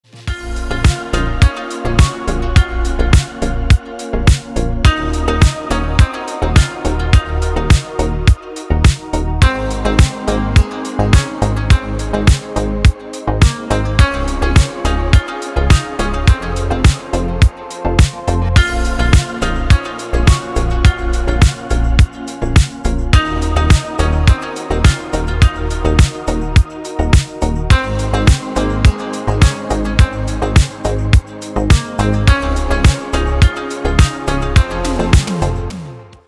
спокойный рингтон 2024